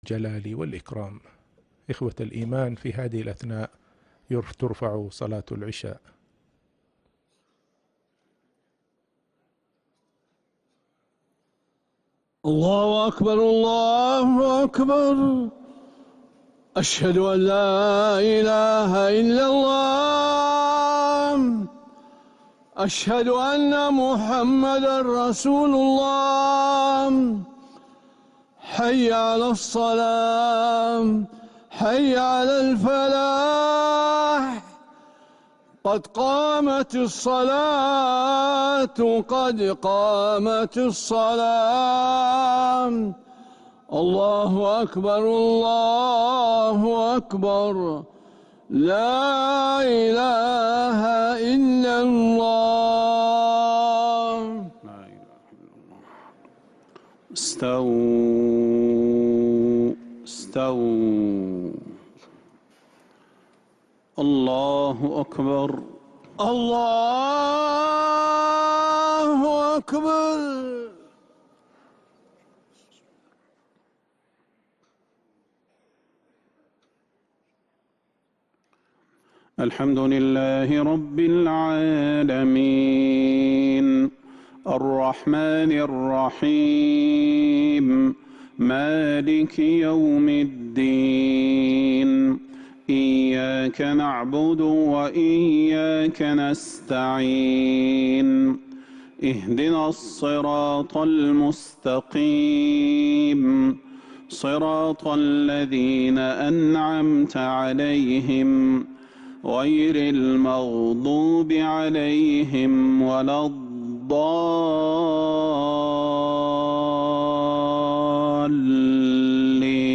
Haramain Salaah Recordings: Madeenah Isha - 01st February 2026
Madeenah Isha (Surah Qaf 16-35) Sheikh Budayr Download 128kbps Audio